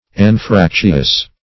Anfractuous \An*frac"tu*ous\, a. [L. anfractuosus, fr. anfractus